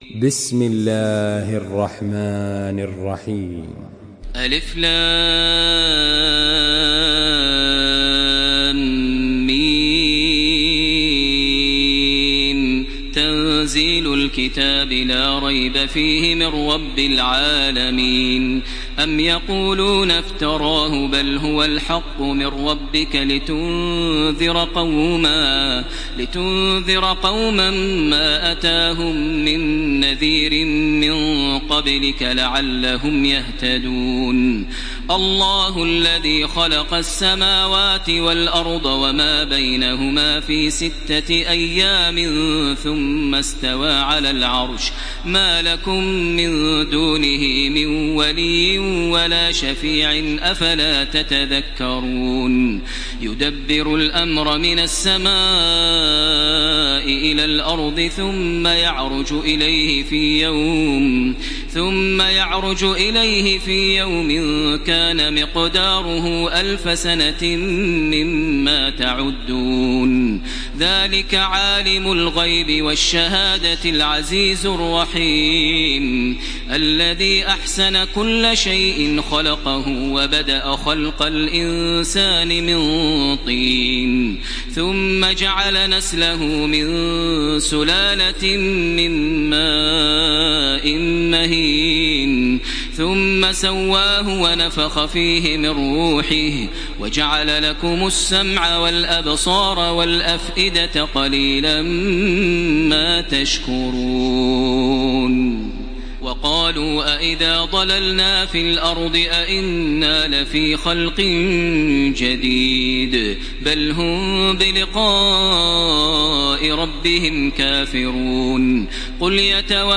Makkah Taraweeh 1434
Murattal